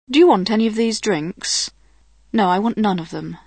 Głosu do nagrań użyczyli profesjonalni lektorzy - rodowici Brytyjczycy!